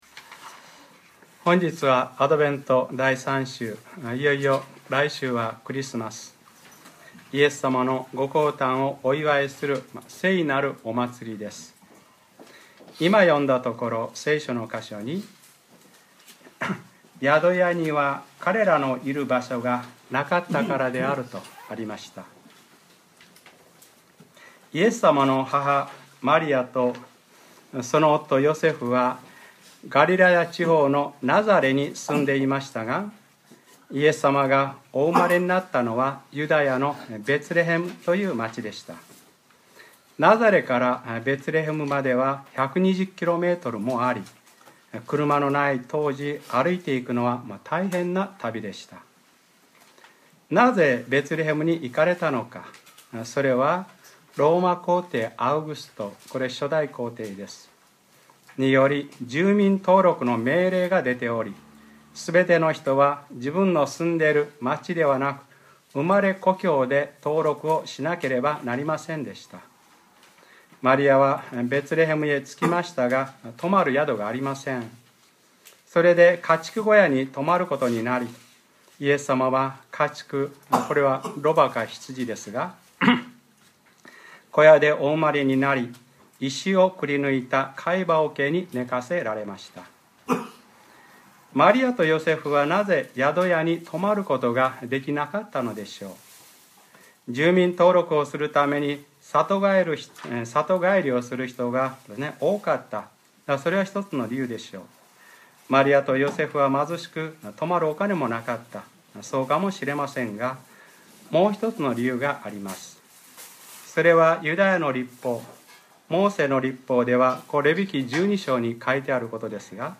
2013年12月15日（日）礼拝説教 『ルカｰ３１：５つのパンと２匹の魚を取り』